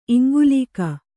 ♪ iŋgulīka